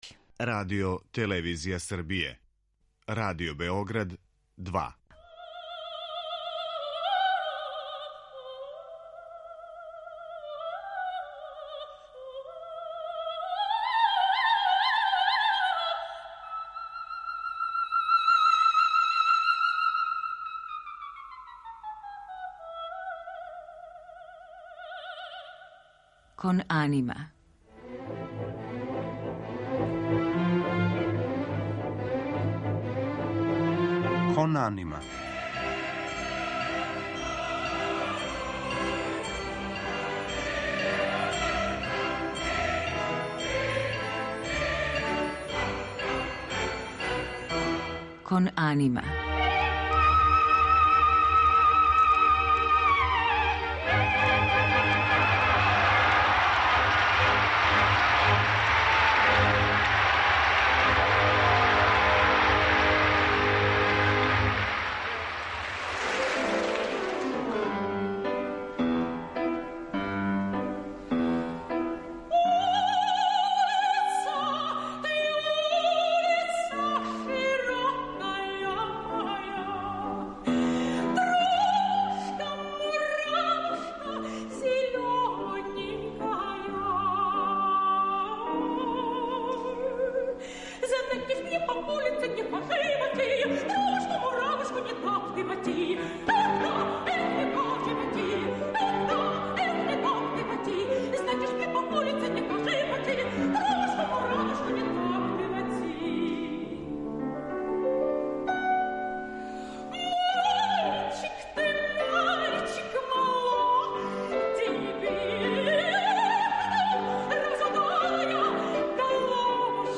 Сопран Хибла Герзмава